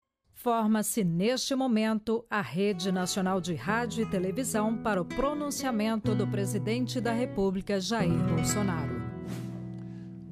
Play Pronunciamento Bolsonaro - SoundBoardGuy
pronunciamento-bolsonaro-audiotrimmer.mp3